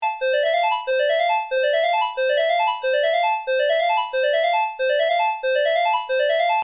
French-guiana-eas-alarm-made-with-voicemod Botão de Som